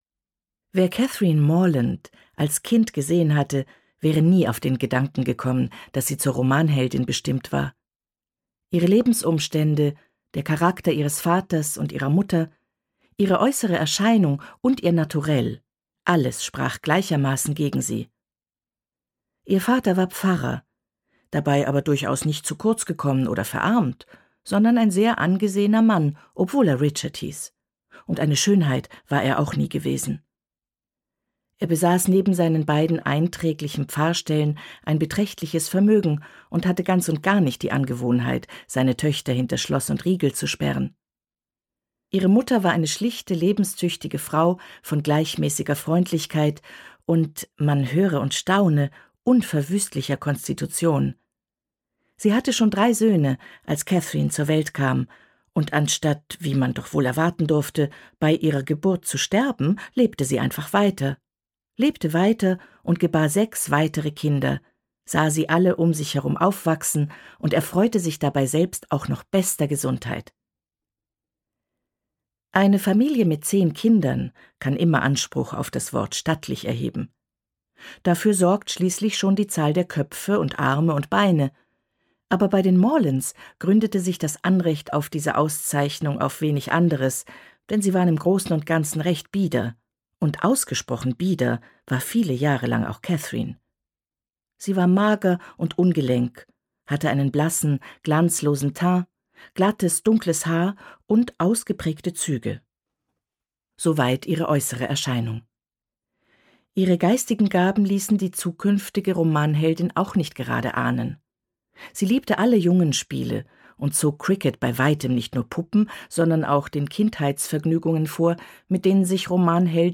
Northanger Abbey Jane Austen (Autor) Eva Mattes (Sprecher) Audio Disc 2021 | 1.